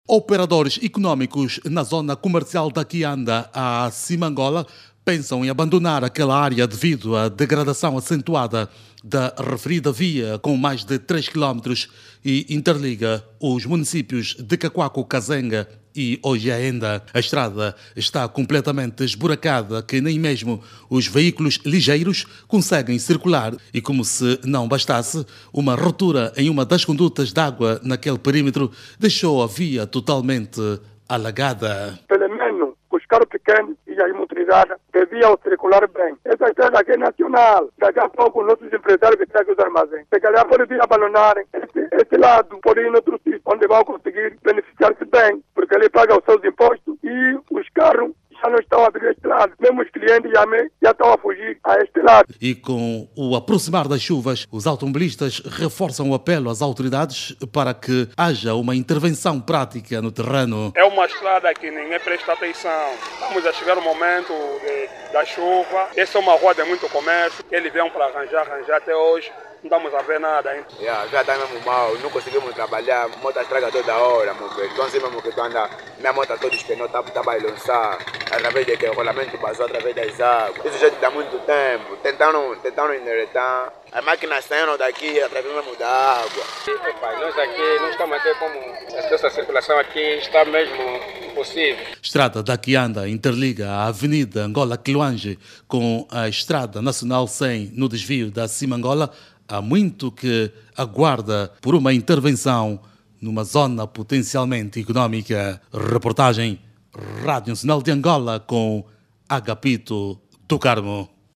Comerciantes e  automobilistas falam em muitos danos as viaturas devido ao estado critico da via e apelam por uma intervenção urgente na estrada.